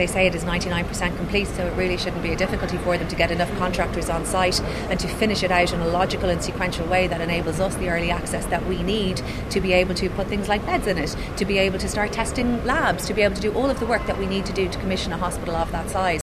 Health Minister Jennifer Carroll MacNeill is refusing to confirm the new completion date: